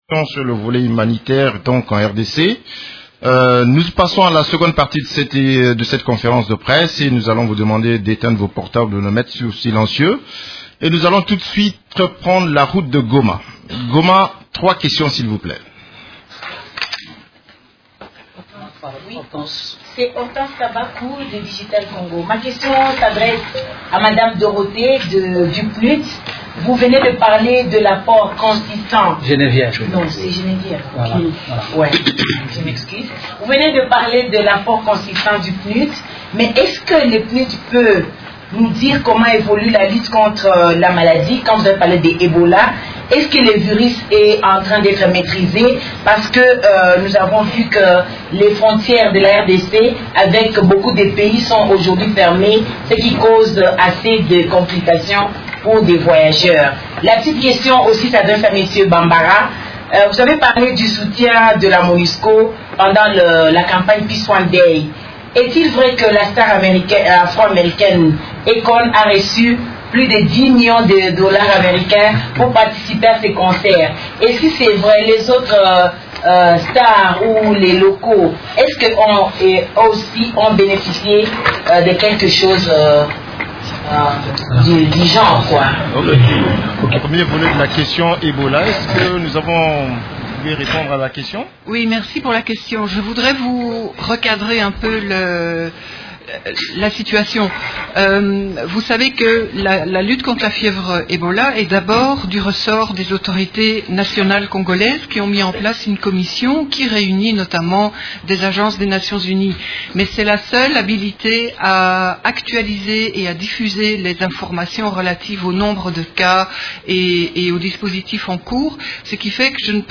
Conférence de presse du 24 septembre 2014